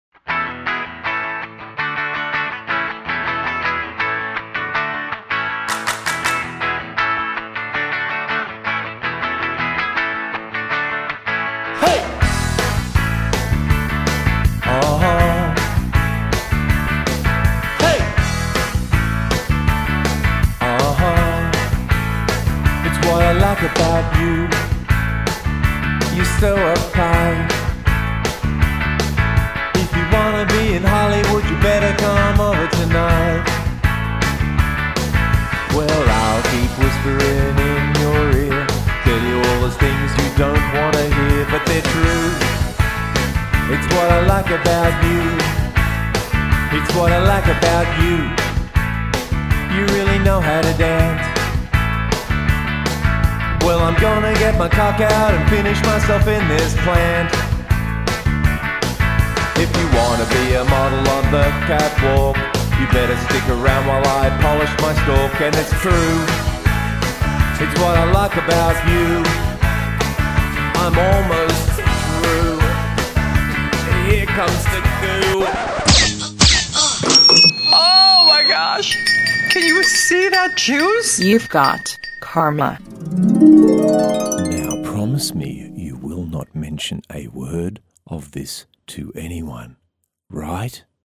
Jingle demo: What I (Dis)Like About Harvey
Vocal needs some work.....but it always will when you can't sing properly in the first place
Vocals are a little too forward in the mix, but I'll try to to a retake once I can actually sing it straight without screwing the words up.